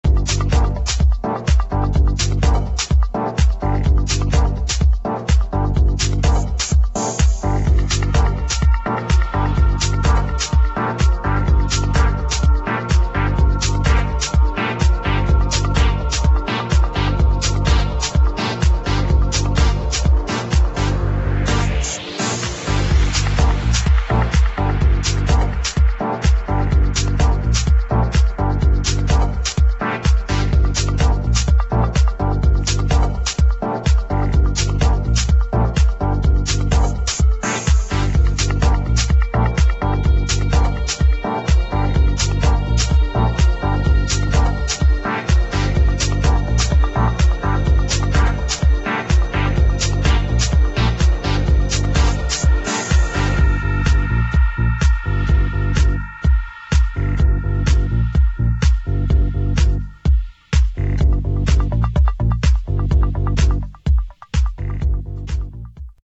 [ TECH HOUSE / PROGRESSIVE HOUSE ]